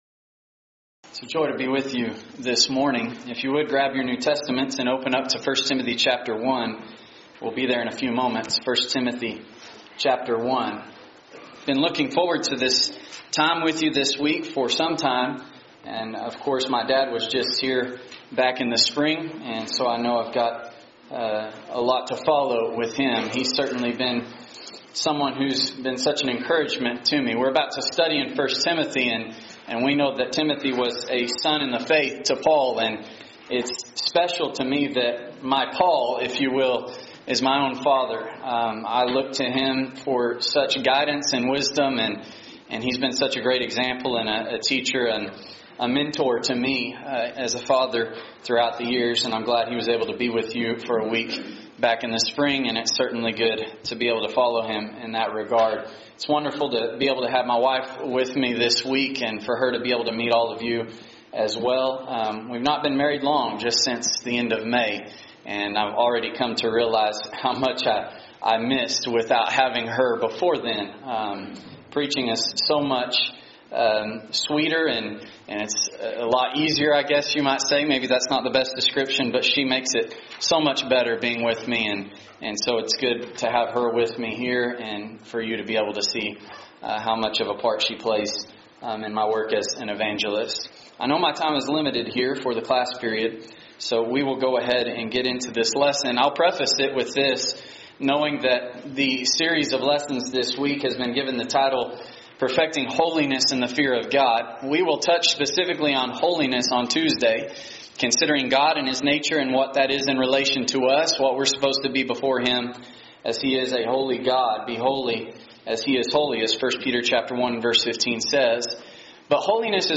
Sermon Archive For 2019